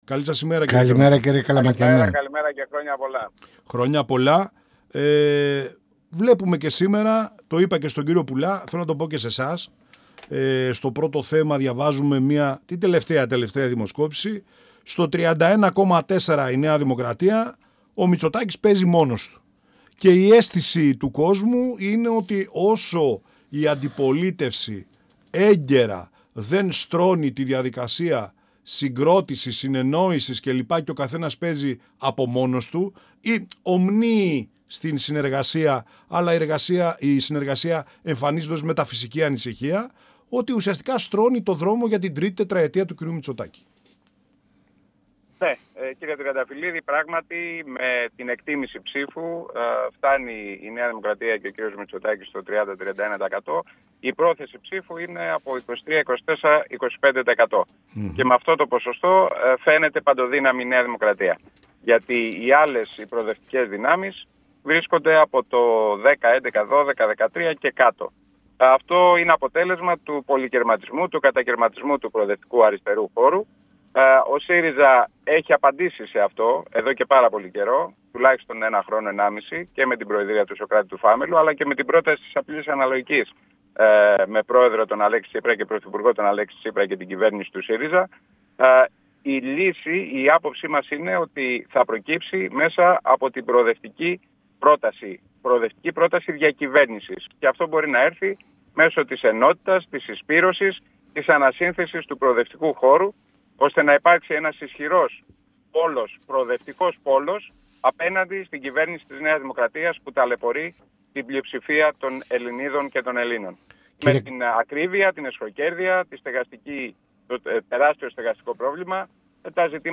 Στην προοπτική του προοδευτικού χώρου και το ισχνό ενδεχόμενο συνεργασίας των κομμάτων της κεντροαριστεράς πριν τις εκλογές, καθώς και η αναμενόμενη δημιουργία του κόμματος του Αλέξη Τσίπρα αναφέρθηκε ο Βουλευτής και Γραμματέας της Κ.Ο. του ΣΥΡΙΖΑ Διονύσης Καλαματιανός, μιλώντας στην εκπομπή «Πανόραμα Επικαιρότητας» του 102FM της ΕΡΤ3.